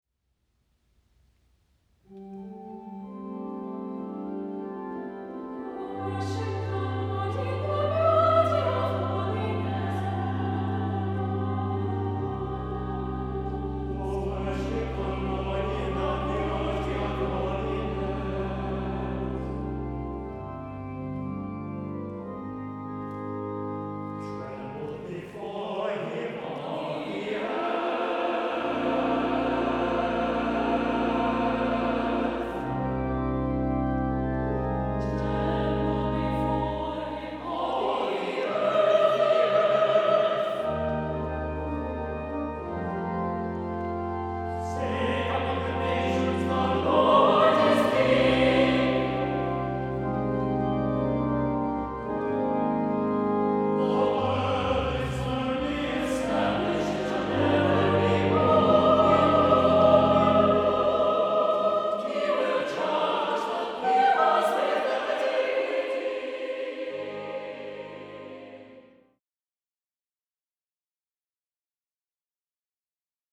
• Music Type: Choral
• Voicing: SATB
• Accompaniment: Organ
set in a gentle 6/8 meter